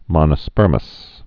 (mŏnə-spûrməs)